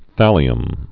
(thălē-əm)